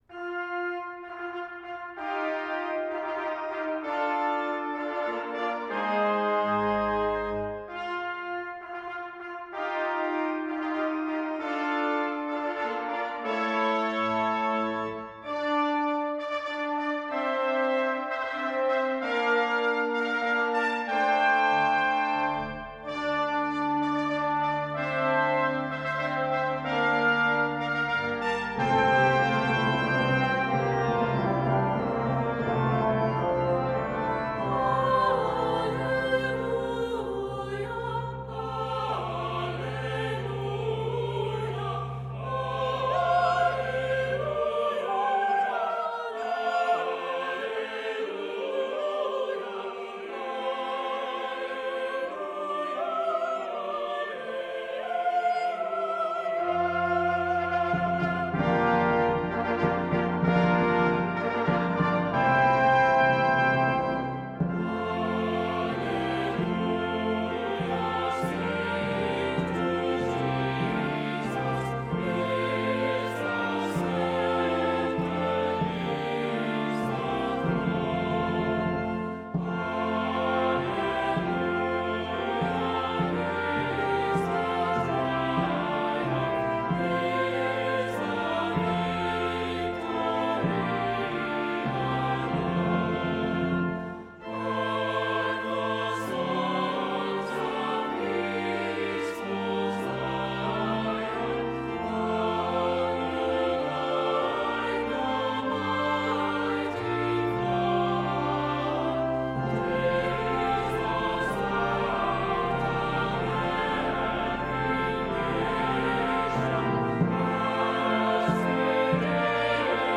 Voicing: "SATB","Assembly"